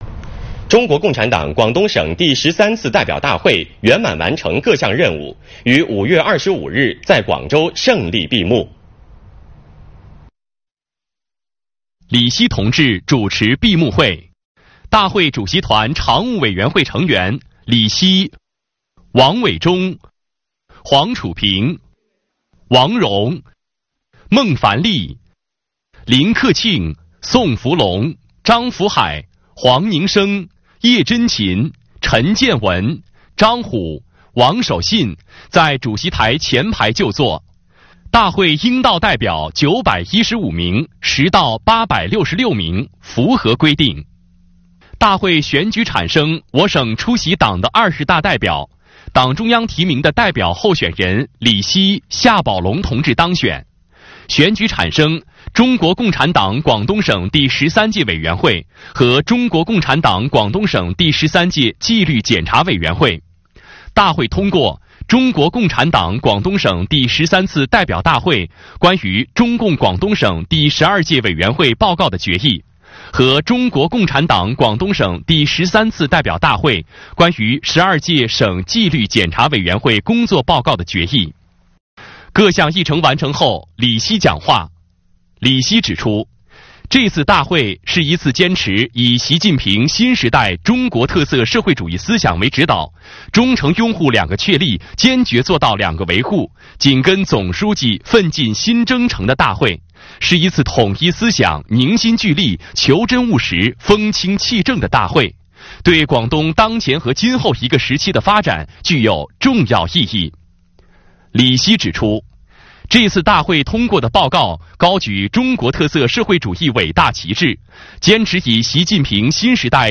（视频来源：广东卫视《新闻联播》）
大会在雄壮的《国际歌》声中胜利闭幕。